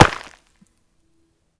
Arrow_impact4.L.wav